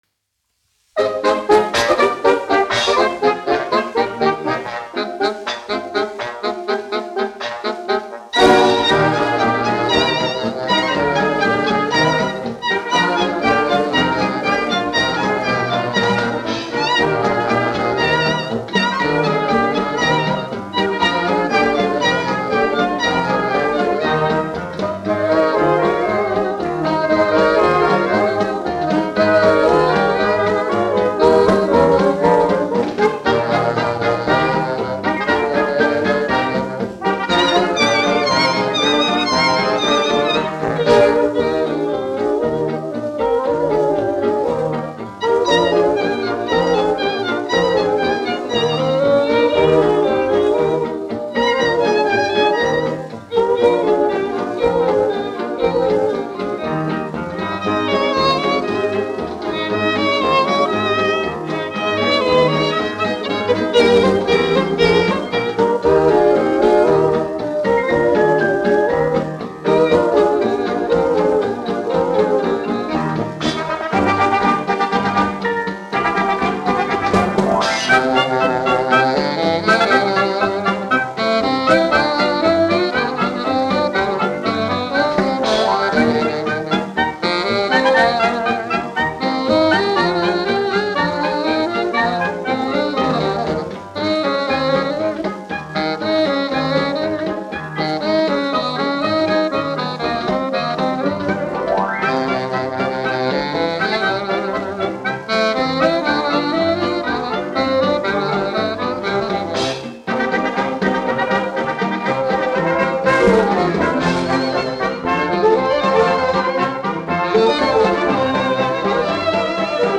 1 skpl. : analogs, 78 apgr/min, mono ; 25 cm
Fokstroti
Latvijas vēsturiskie šellaka skaņuplašu ieraksti (Kolekcija)